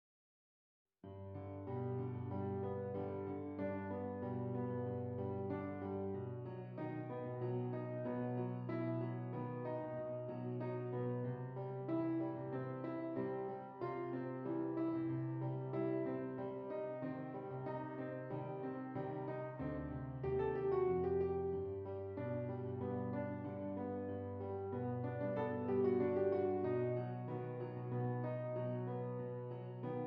G Major
Moderate